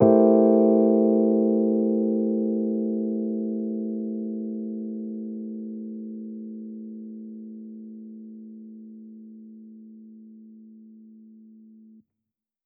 Index of /musicradar/jazz-keys-samples/Chord Hits/Electric Piano 2
JK_ElPiano2_Chord-Am7b9.wav